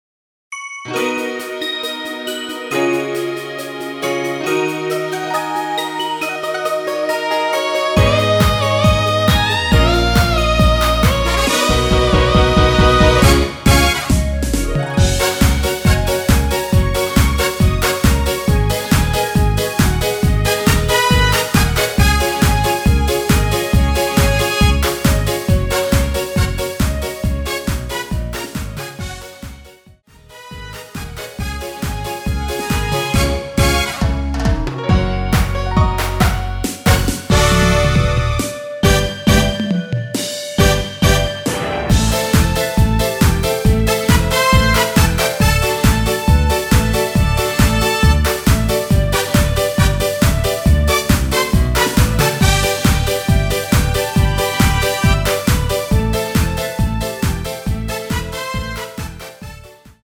멜로디 포함된 남성분이 부르실수 있는 키로 제작 하였습니다.(미리듣기 참조)
F#
앞부분30초, 뒷부분30초씩 편집해서 올려 드리고 있습니다.
중간에 음이 끈어지고 다시 나오는 이유는